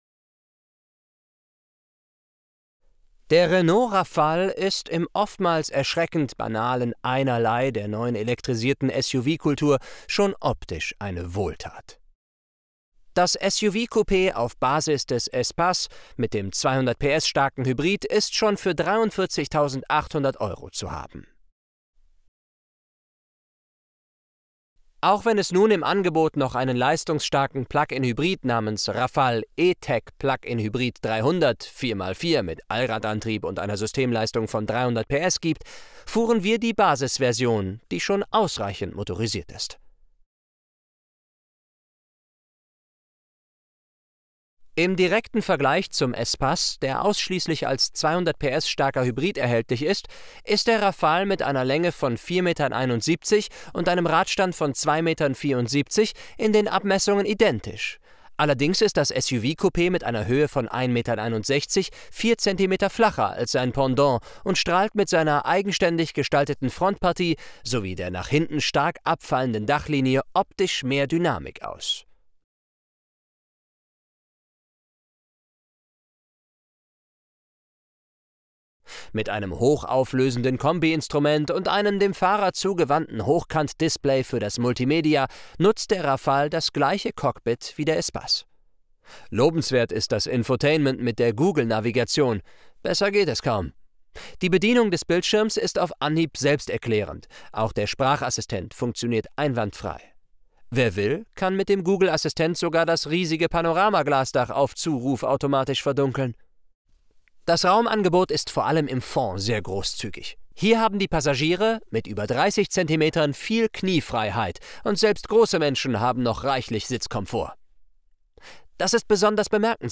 Downloads Deutsch (1080p H.264) Deutsch (720p H.264) Deutsch (360p H.264) Deutsch (Voiceover WAV) Atmo/Clean (1080p H.264) Atmo/Clean (720p H.264) Atmo/Clean (360p H.264)